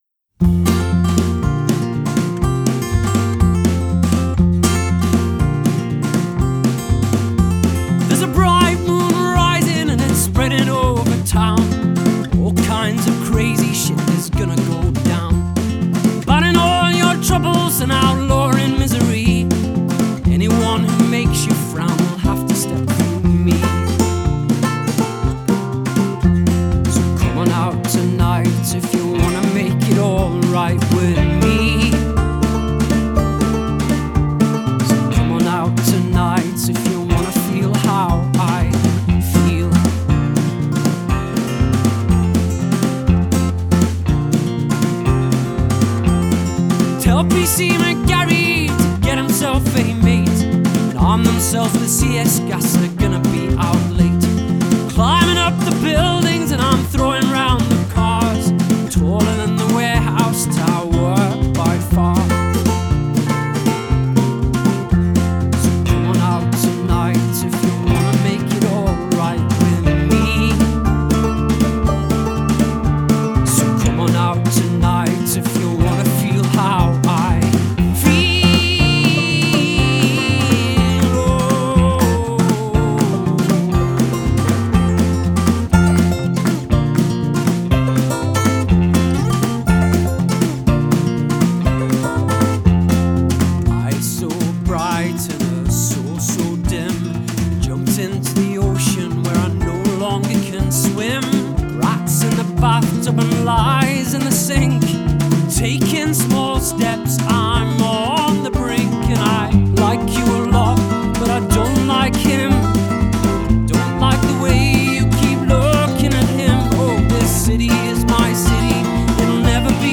Genre: Indie Rock/ Indie Folk